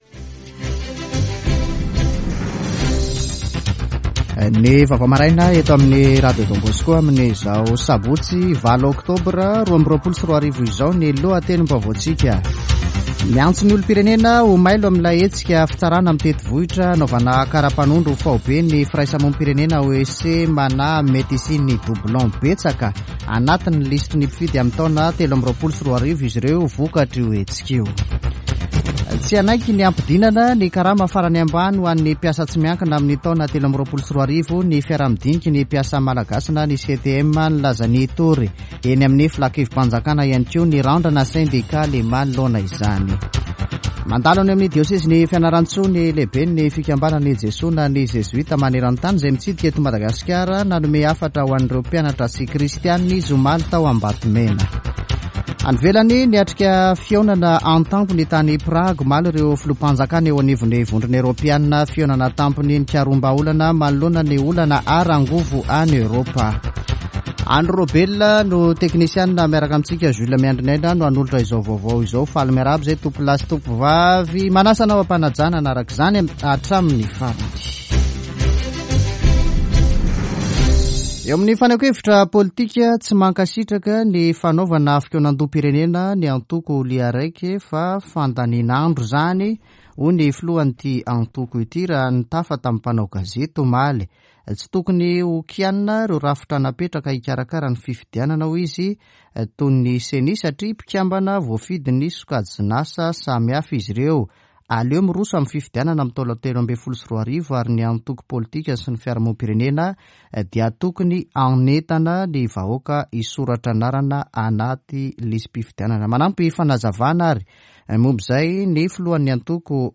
[Vaovao maraina] Sabotsy 8 ôktôbra 2022